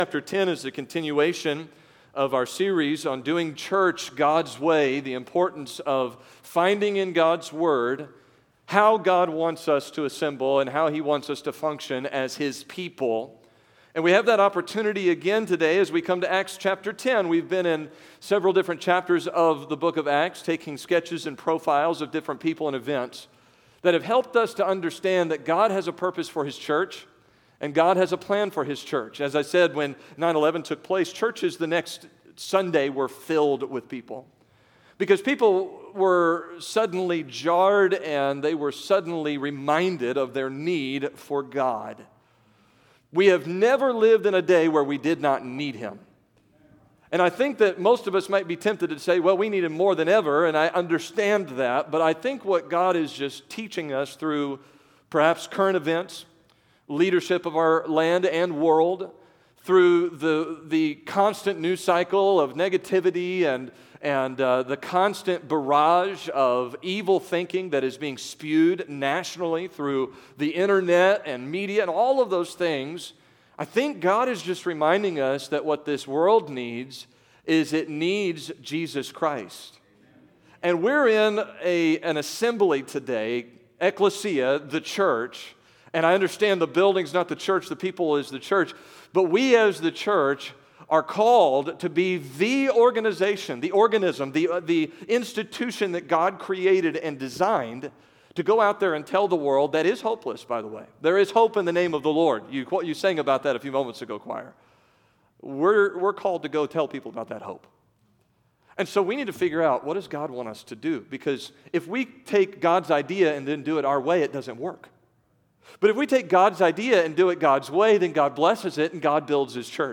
September 2022 Sunday Morning Scripture: Acts 10:1-46 Download: Audio